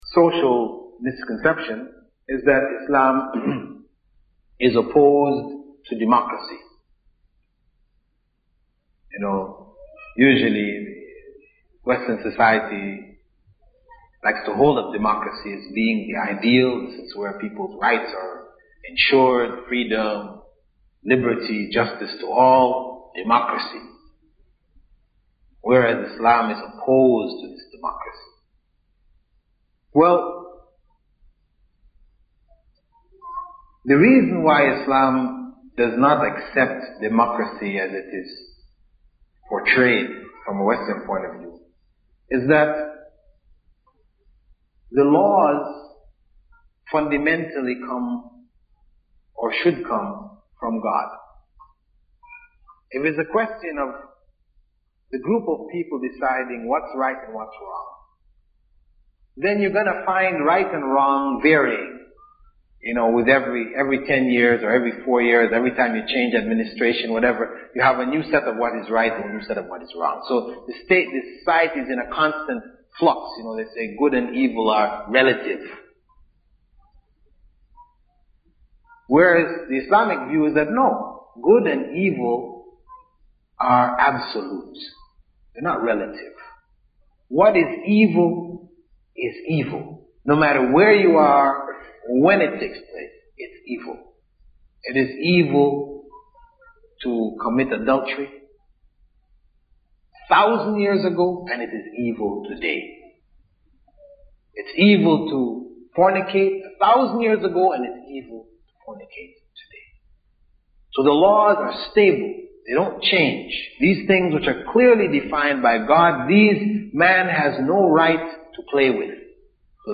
A lecture in English delivered by Sh.